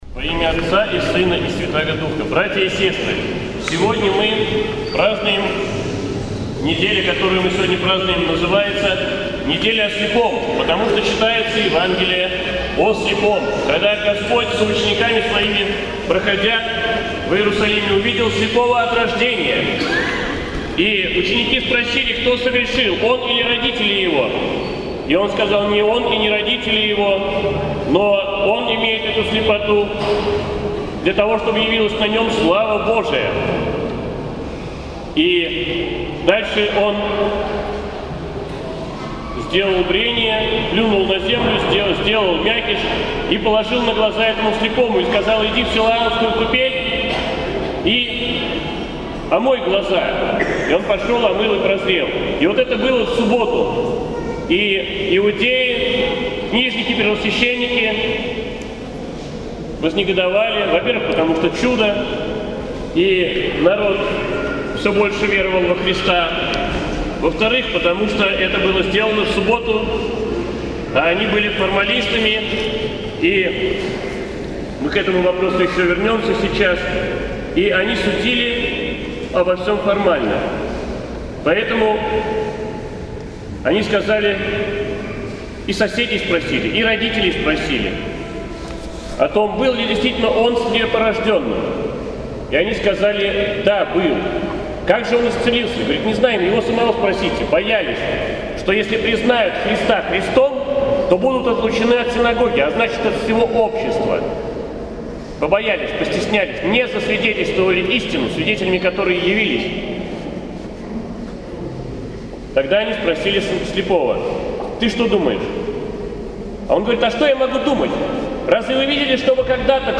Проповедь в неделю о слепом